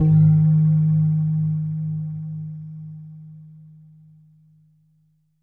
LEAD G#1.wav